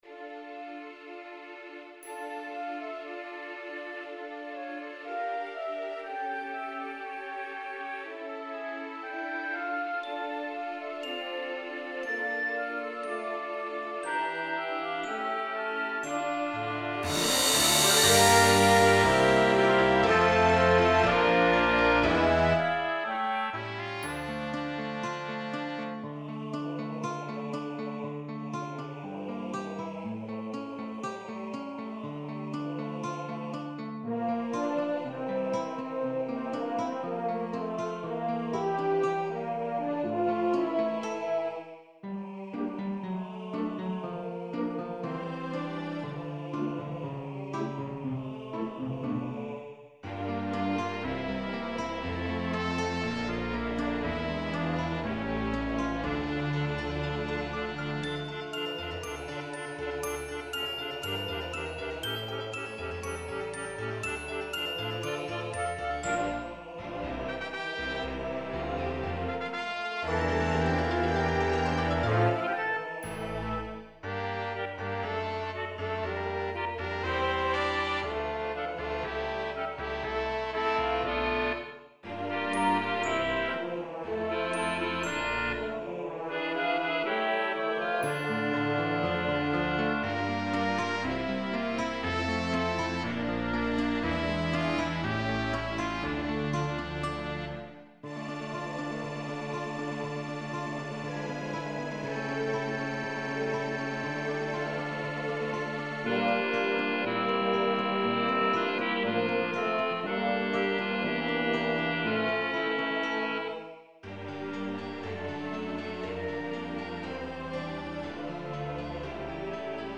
Root > _Разное > Kompozitory_new > Комиссаров В > Симфонический > Узкою лентой